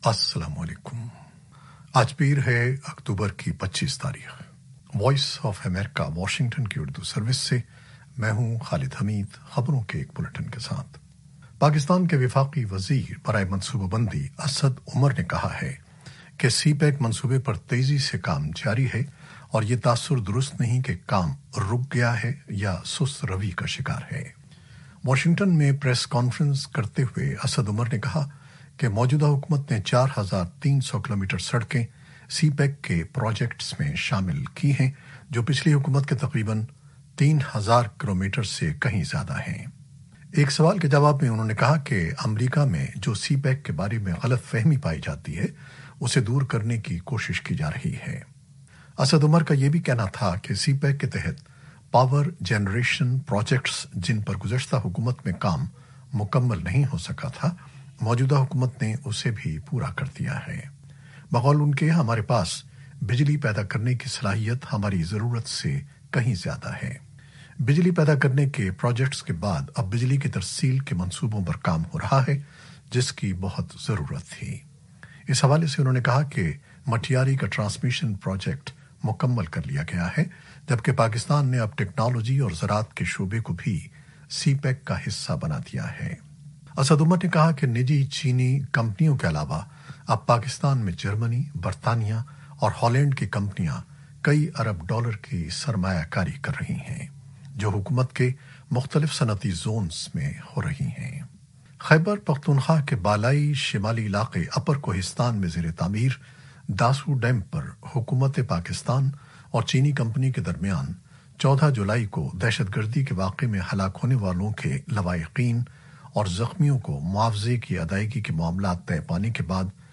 نیوز بلیٹن 2021-25-10